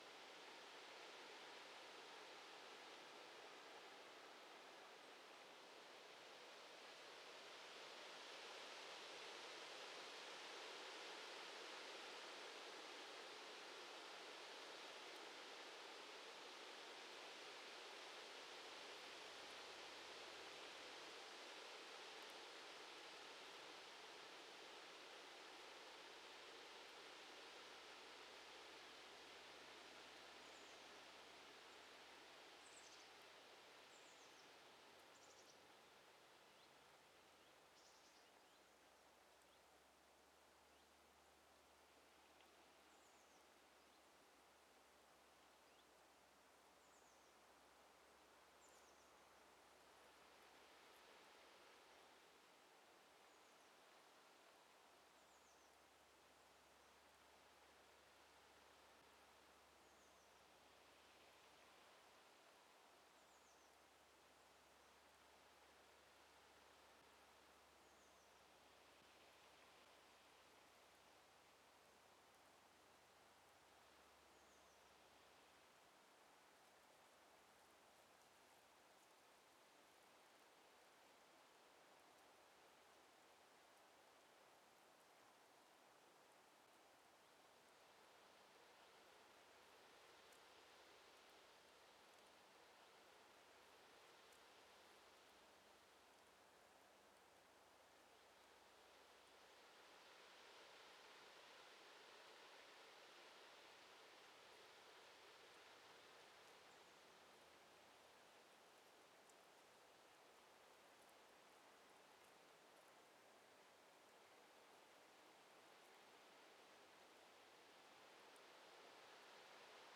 Wind in Reeds / Cattails. Clean ambience with a faint, brief overhead aircraft pass. Occasional Songbirds. Loop
Wind in Reeds and Cattails – Hofsnäs
Loop Category WIND Subcategory GENERAL Location Torpanäset Recorded 2025-10-27 15:55:51 Duration 360 sec Loopable No Download MP3